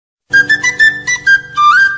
En esta ocasión os traemos un cover en versión flauta